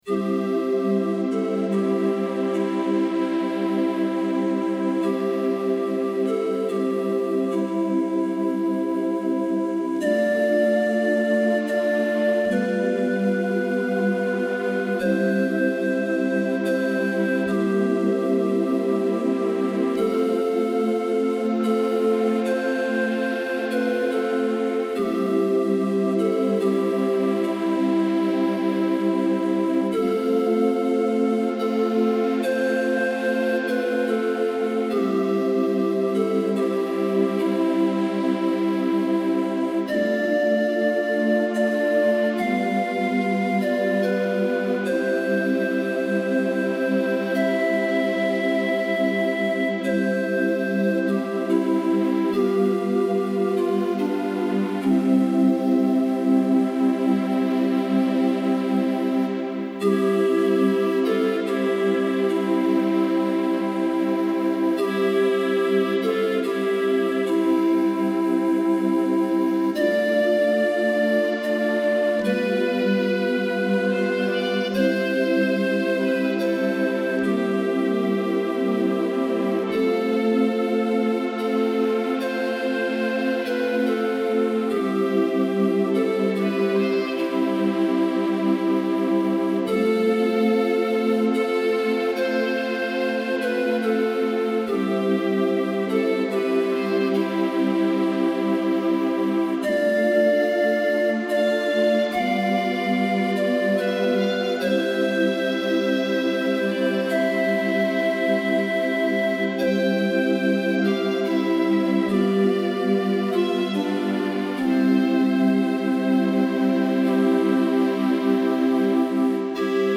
Some Christmas ditties that I created oodles of years ago on a Yamaha V50 (All tunes arranged and performed by me)